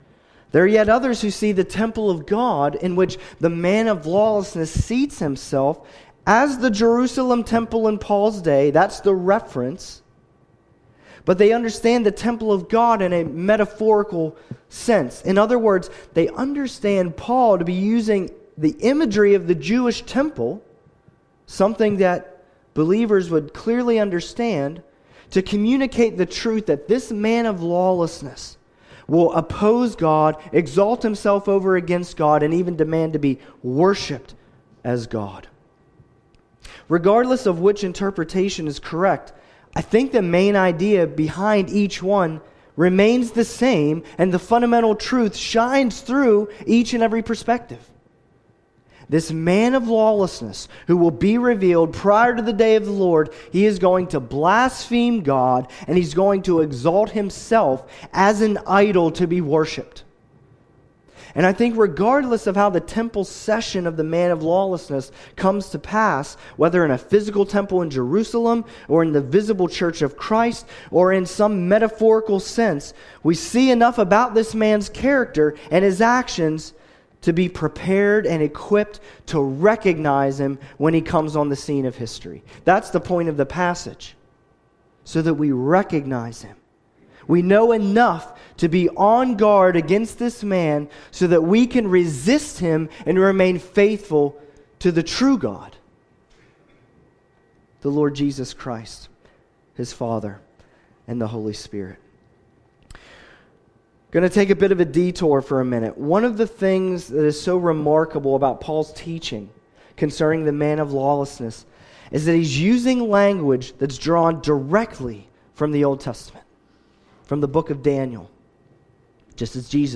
(partial recording)